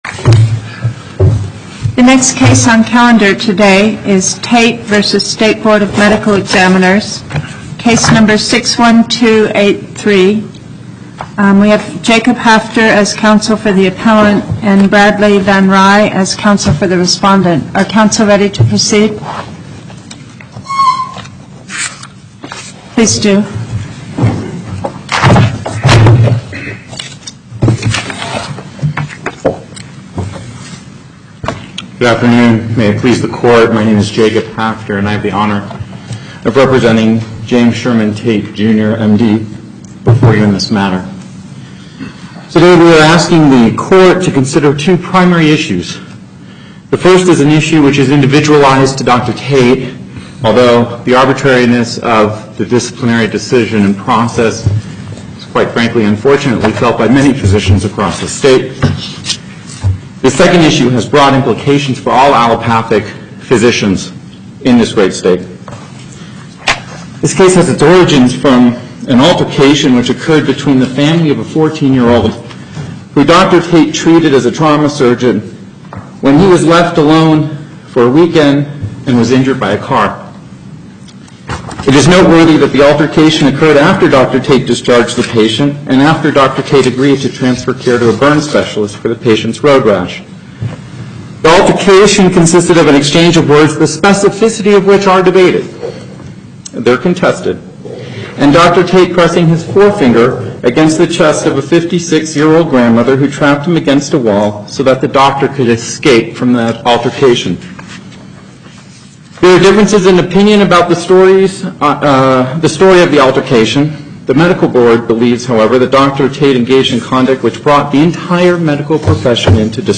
Location: Carson City Before the En Banc Court, Chief Justice Pickering Presiding